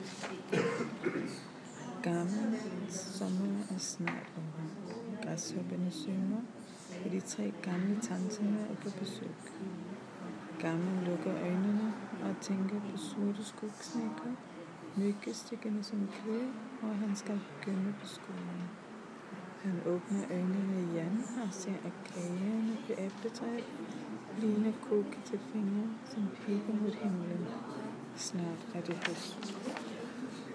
Højtlæsning